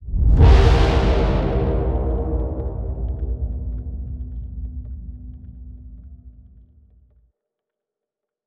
impact_horn_04.wav